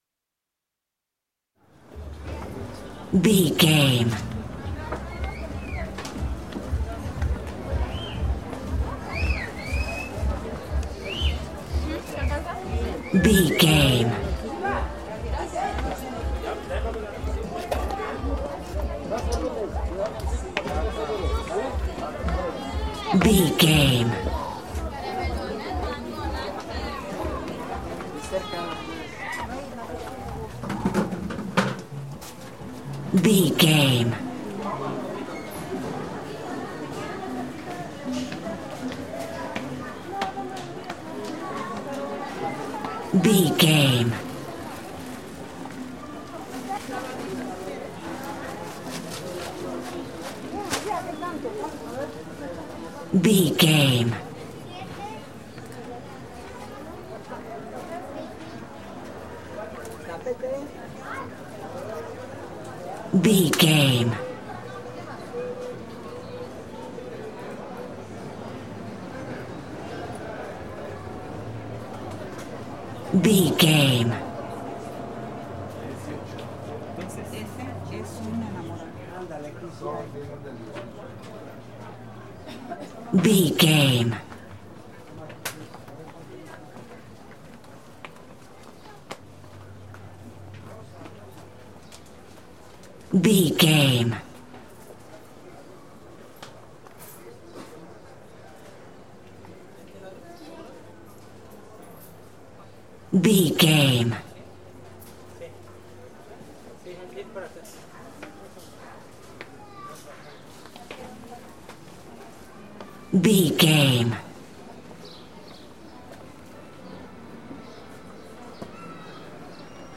Mexico taxco street market
Sound Effects
urban
chaotic
ambience